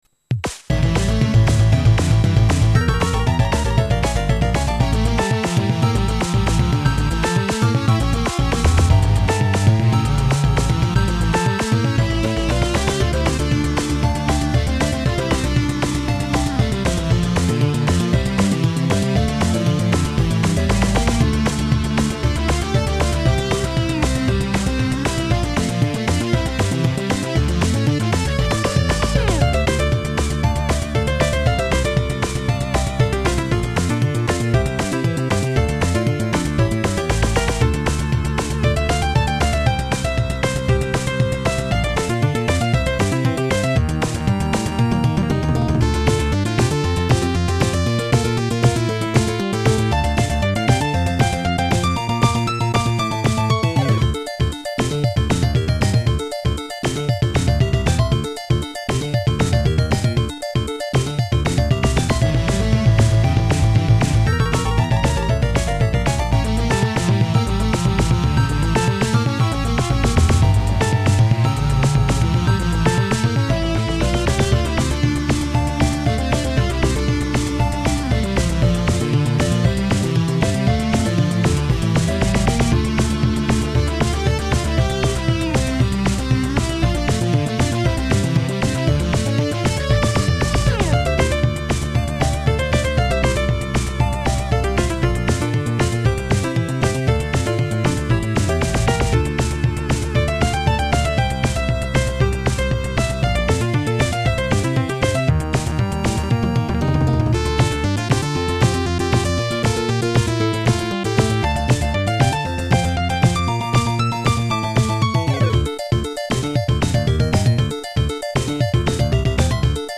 ※データは全てSC-88Pro専用です